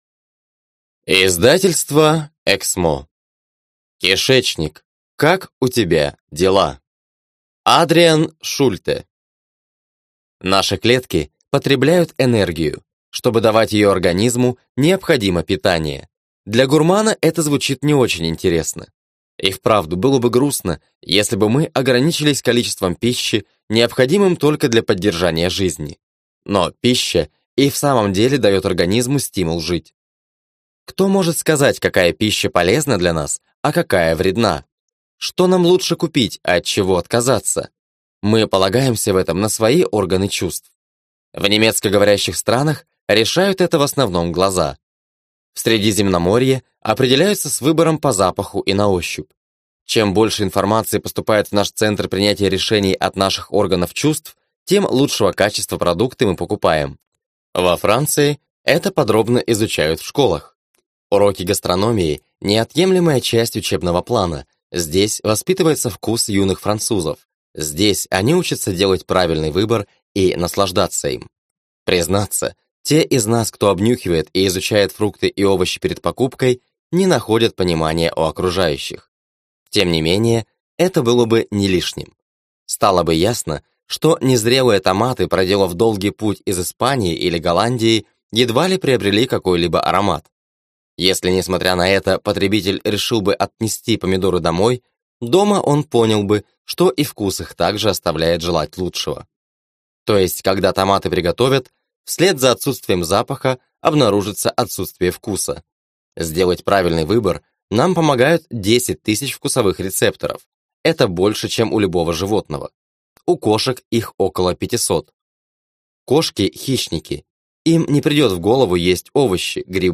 Аудиокнига Кишечник. Как у тебя дела?